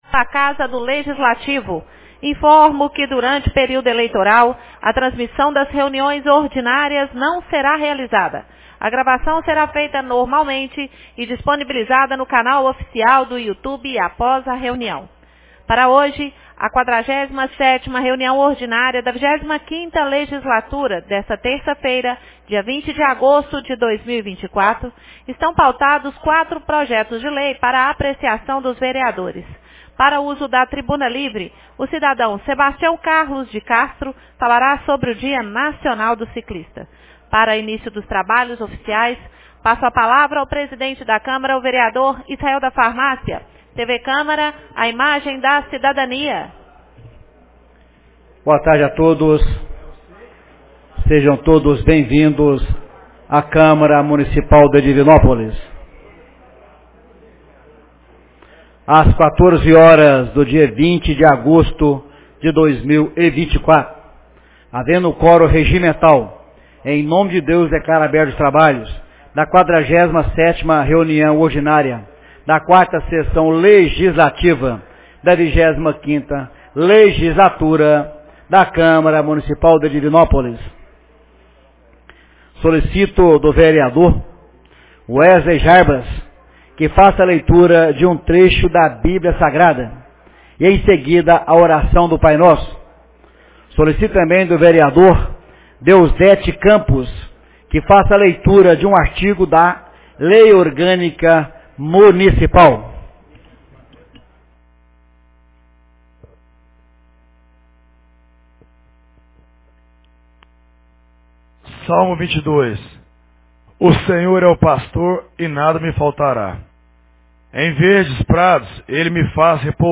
47ª Reunião Ordinária 20 de agosto de 2024